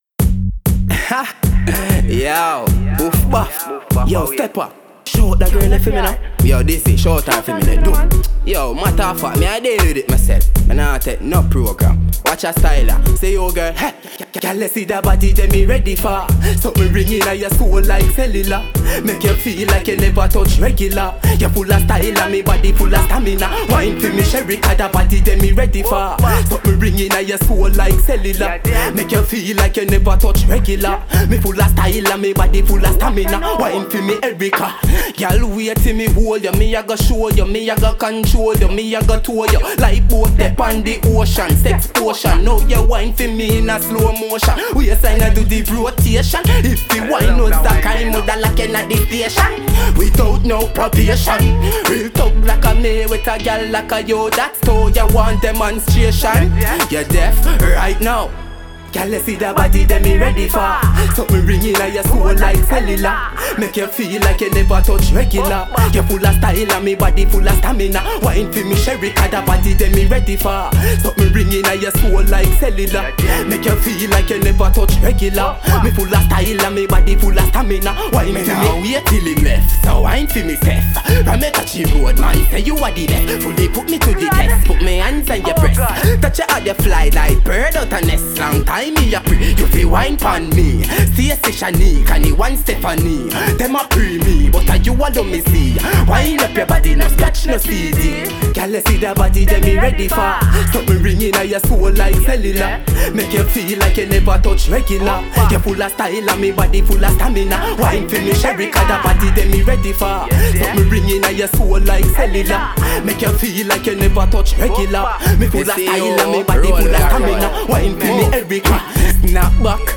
dancehall
reggae